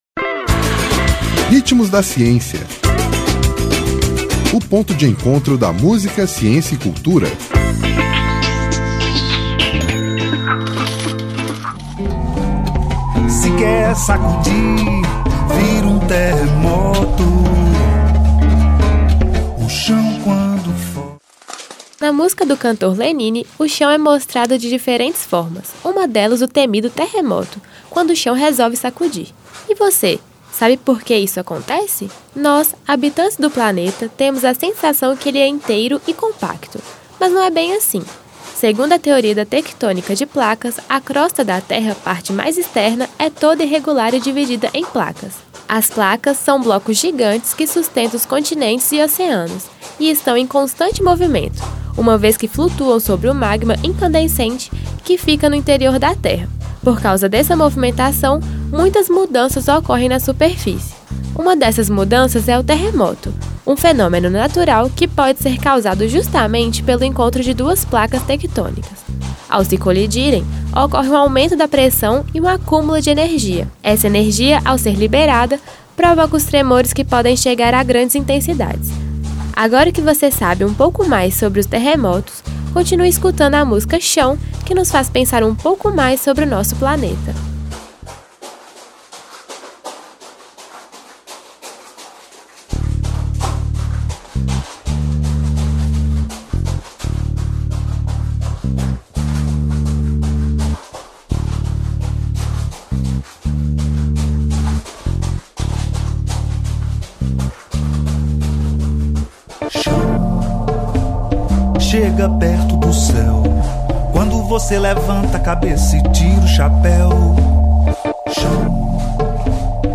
Intérprete: Lenine